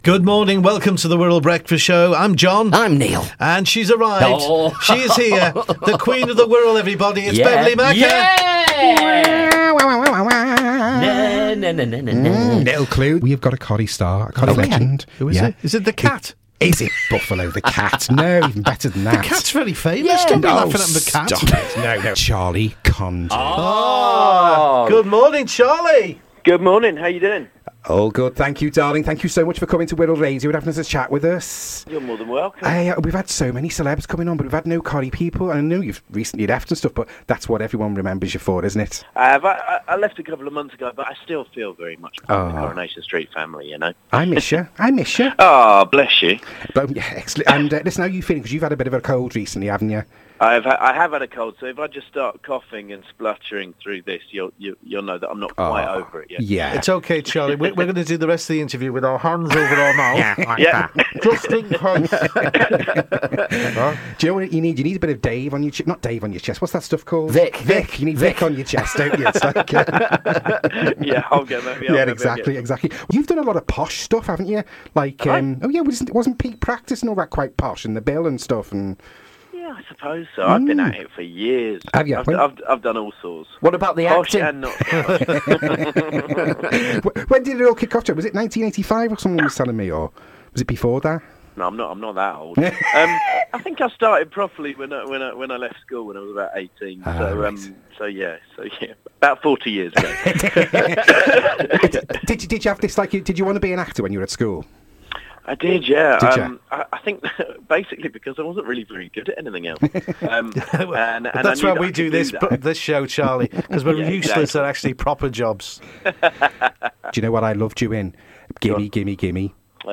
part of the Wirral Radio Breakfast Show.